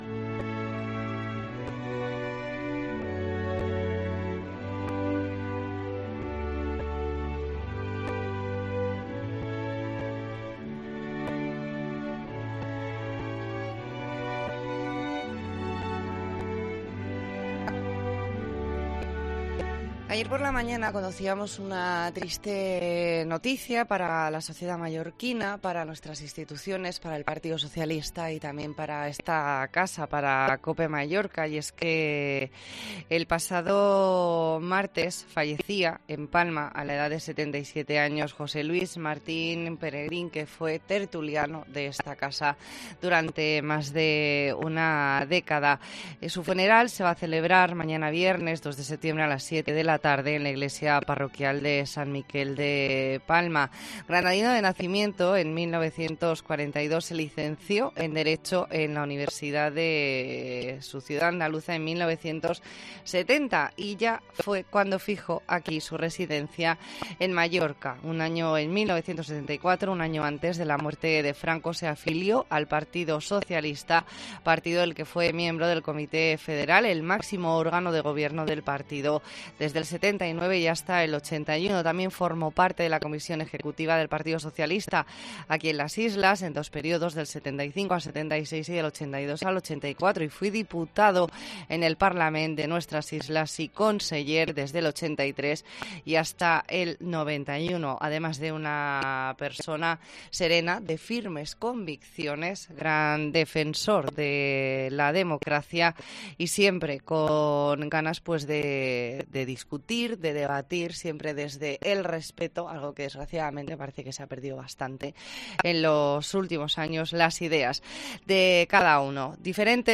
Sus colegas de tertulia le dedican unas últimas palabras